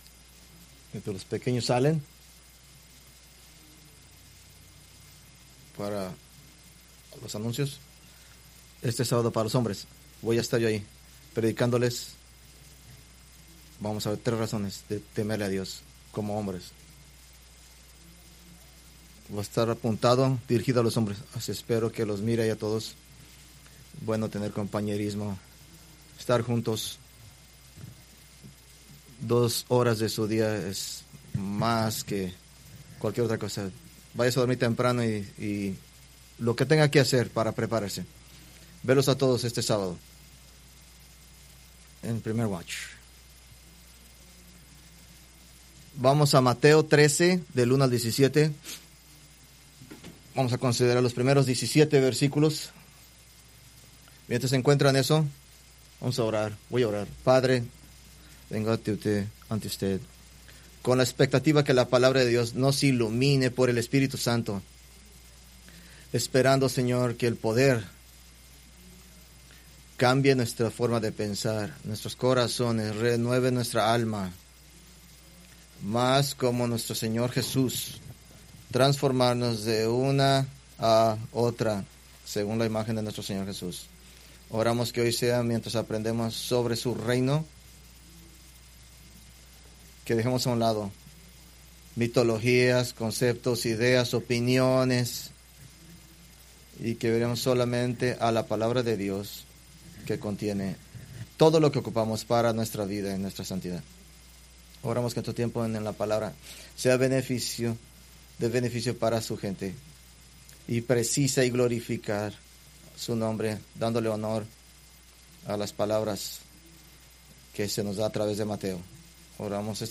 Preached February 1, 2026 from Mateo 13:1-17